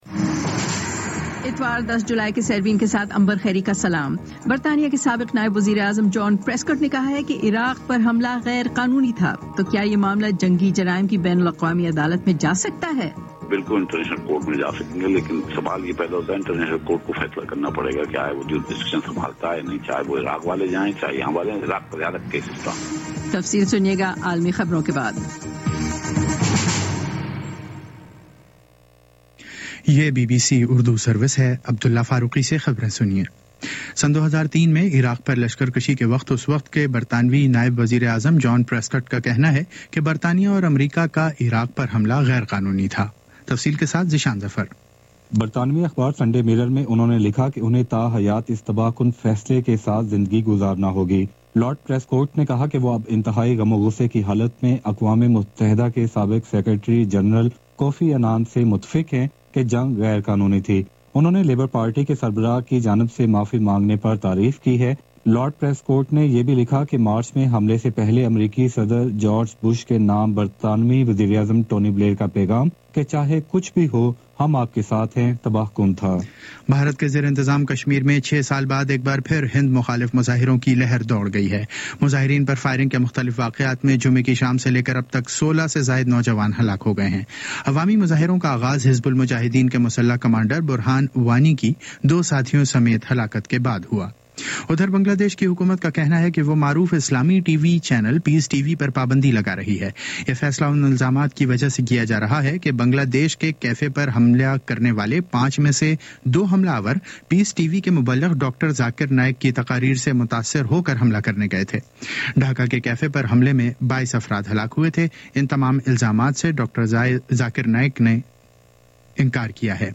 اتوار 10 جولائی کا سیربین ریڈیو پروگرام